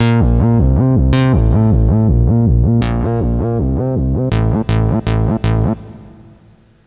basschorus.aiff